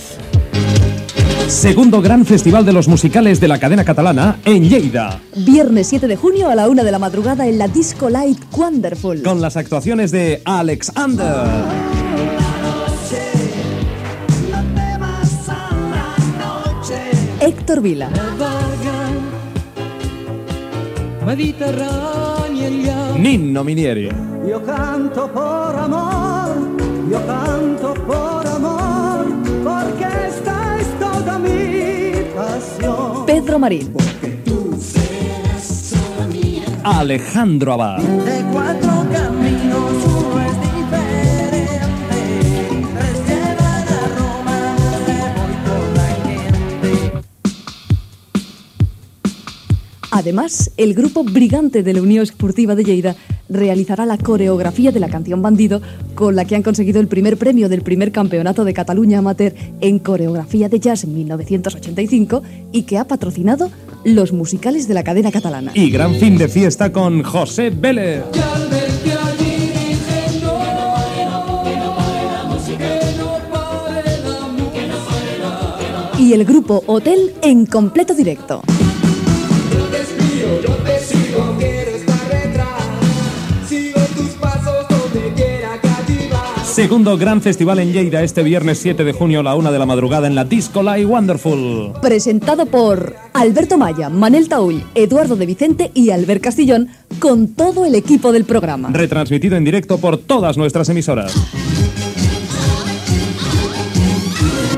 Radio España de Barcelona FM
Musical